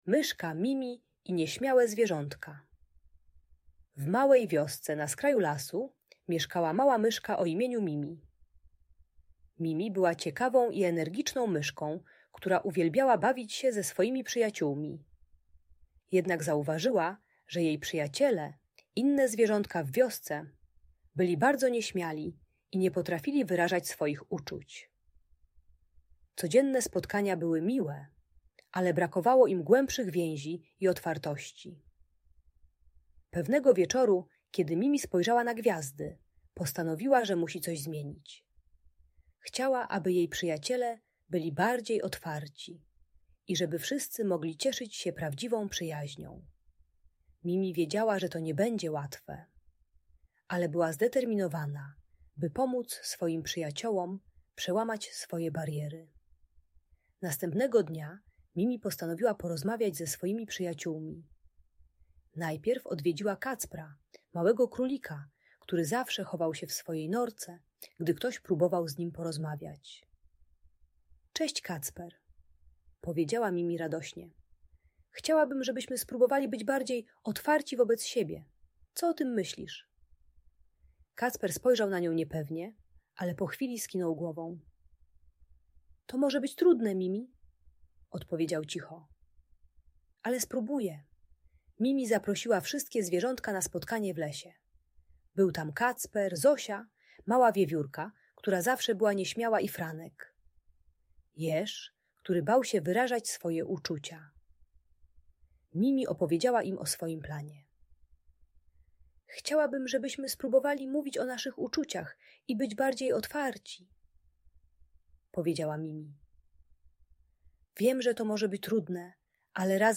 Bajka dla nieśmiałego dziecka w wieku 4-6 lat, które ma trudności z wyrażaniem uczuć i nawiązywaniem przyjaźni. Ta audiobajka o nieśmiałości pomaga dziecku zrozumieć, że można okazywać troskę na różne sposoby.